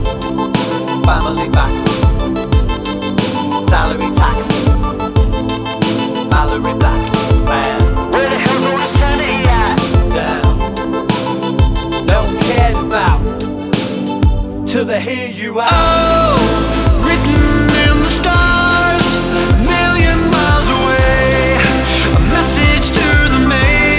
for full male rap and sing R'n'B / Hip Hop 3:47 Buy £1.50